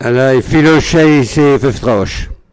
Localisation Île-d'Olonne (L')
Catégorie Locution